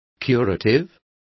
Complete with pronunciation of the translation of curative.